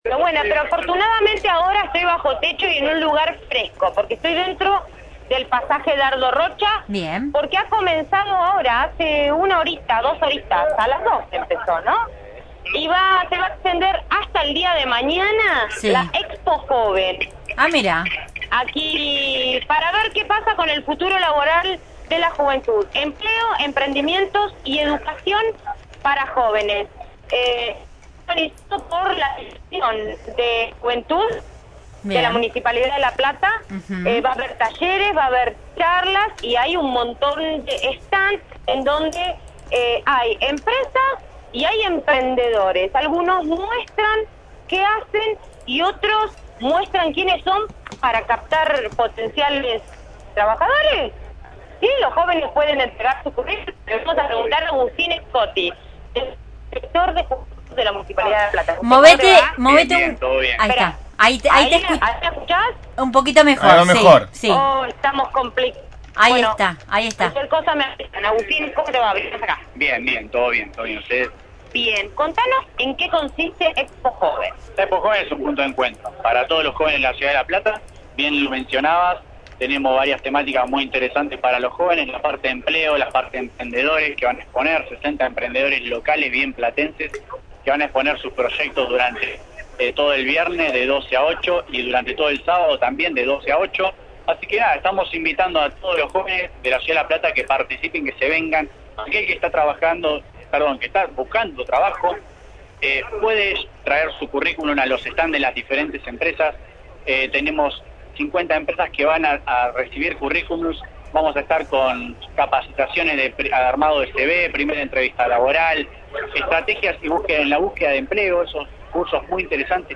desde el Pasaje Dardo Rocha con la ExpoJoven 2016